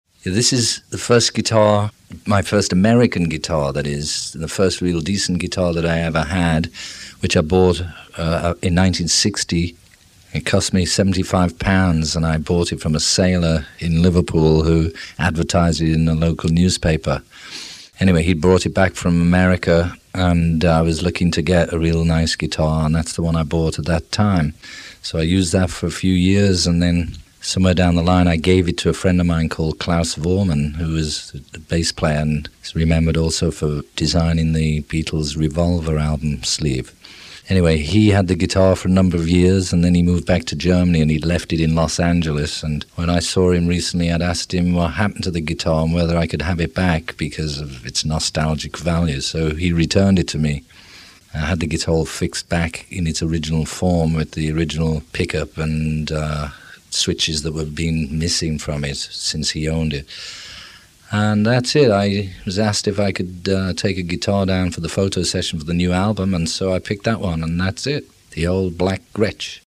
The app features recordings of Harrison talking about and playing each instrument.